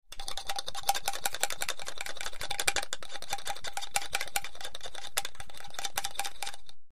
BeakerStirMetalFas PE266004
Beaker; Stir 4; A Metal Stirrer Briskly Stirring A Liquid In A Plastic Beaker; Close Perspective. Pharmacy, Lab.